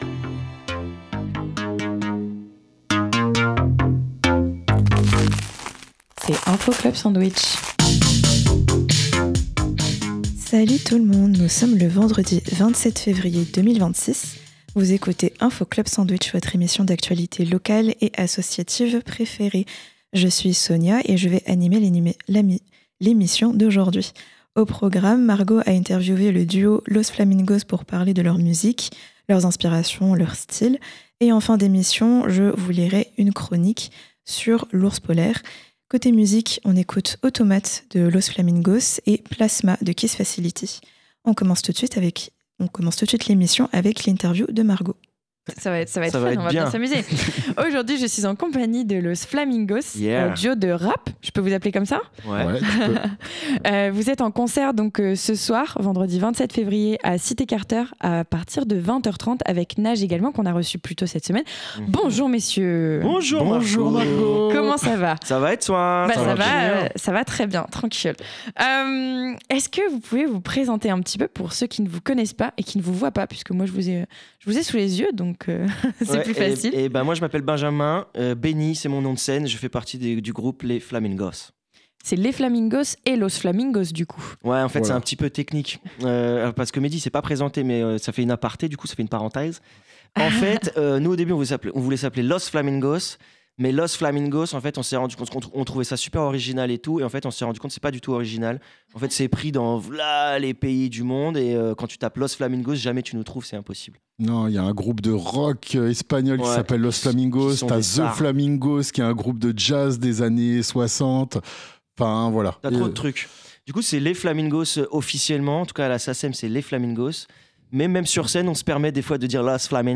Dans l'Info Club Sandwich de cette fin de semaine, on écoute l'interview de Los Flamingos qui est venu nous parler de leur musique et de leur actus.